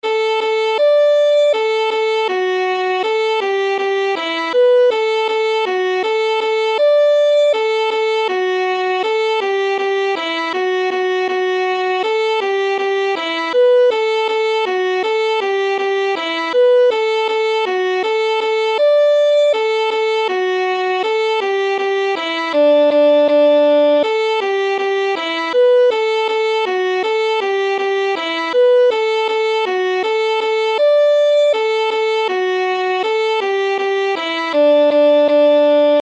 Aranžmá Noty na housle
Hudební žánr Lidovky